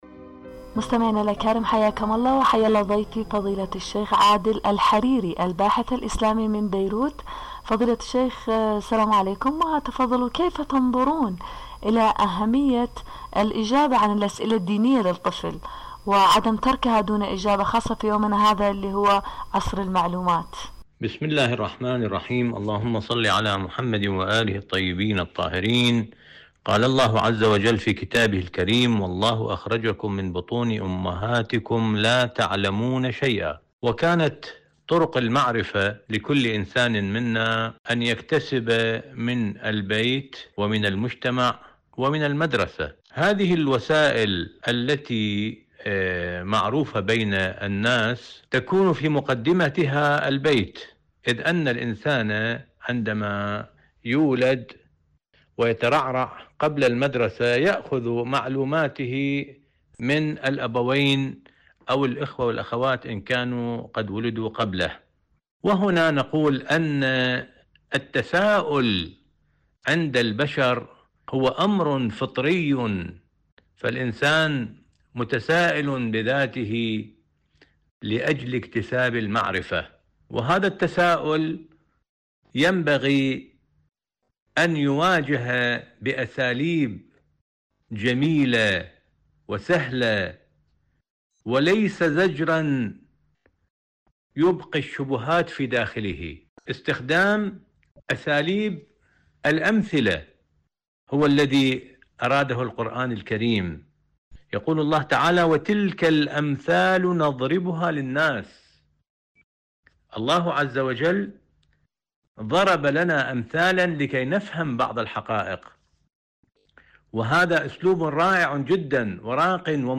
إذاعة طهران- عالم المرأة: مقابلة إذاعية